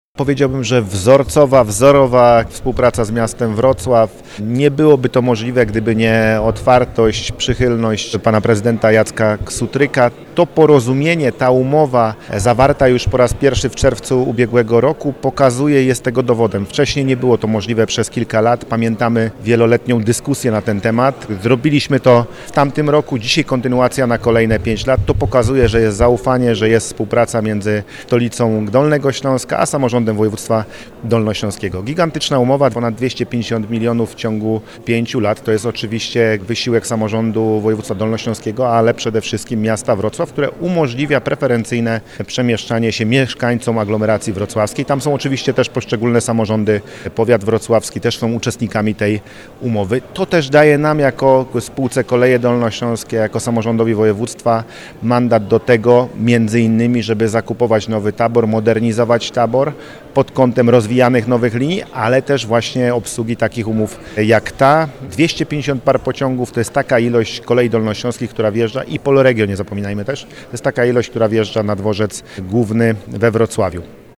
Rozwój zrównoważonego i dostępnego transportu publicznego jest naszym wspólnym celem – mówi Paweł Gancarz, Marszałek Województwa Dolnośląskiego.